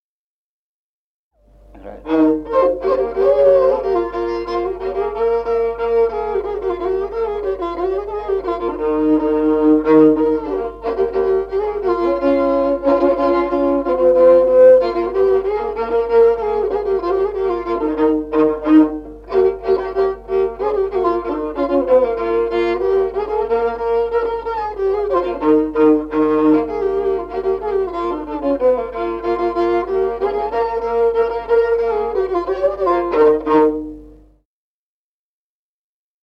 Музыкальный фольклор села Мишковка «Марш, от венца», партия 2-й скрипки.